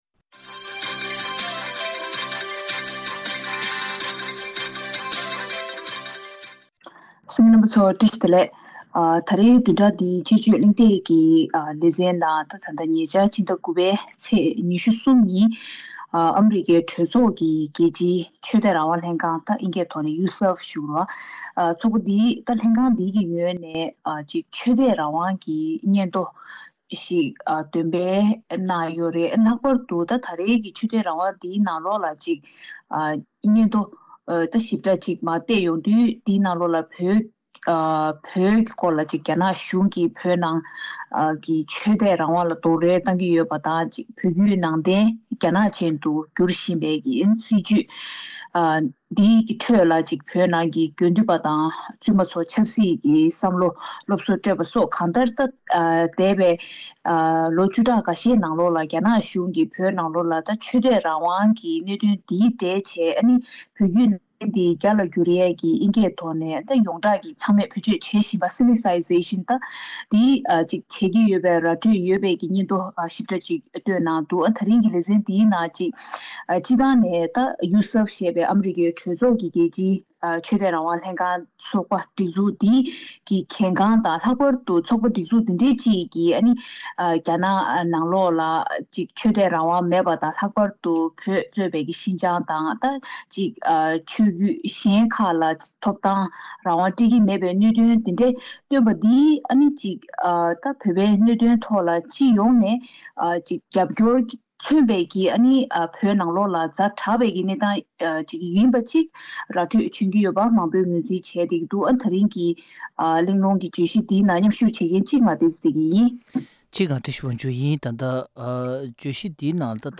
དཔྱད་གཞིའི་གླེང་མོལ་ཞུས་པར་གསན་རོགས་གནང་།།